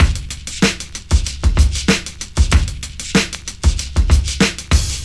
• 95 Bpm 2000s Breakbeat D# Key.wav
Free drum loop - kick tuned to the D# note. Loudest frequency: 1558Hz